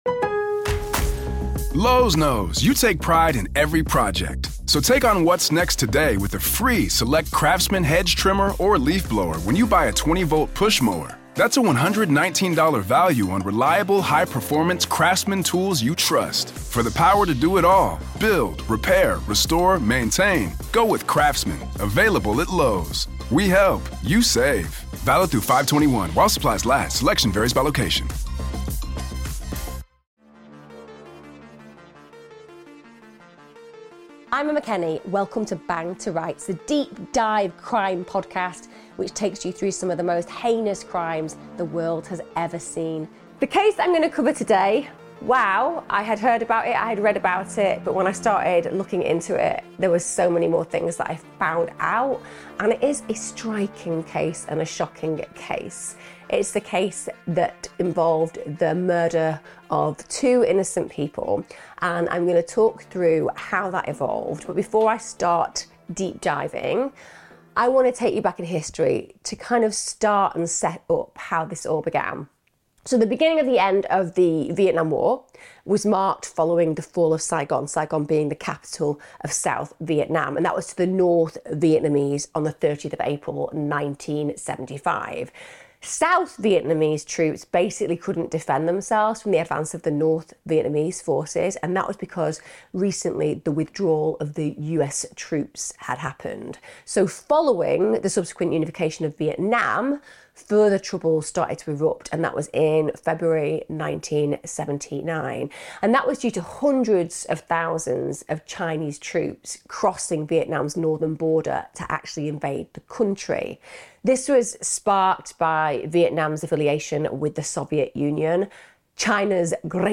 Featuring expert interviews, psychological insights, and a detailed chronology of events, this "Bang to Rights" episode offers a comprehensive examination of the Jennifer Pan case.